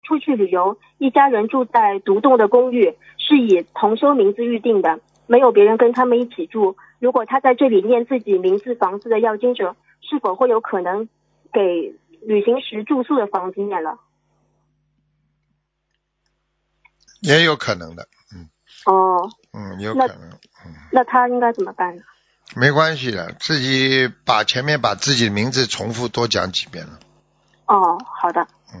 目录：☞ 剪辑电台节目录音_集锦